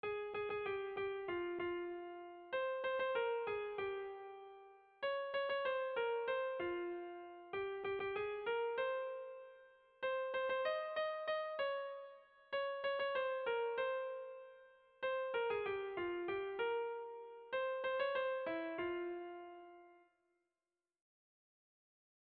Erlijiozkoa
Zortziko txikia (hg) / Lau puntuko txikia (ip)